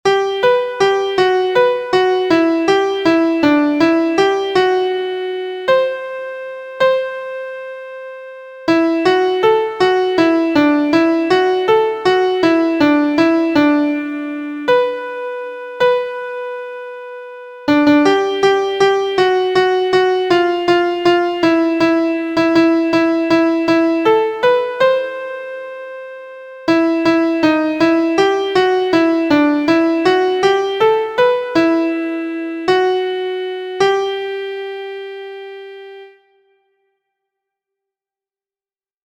• Key: G Major
• Time: 3/4
• Form: ABCD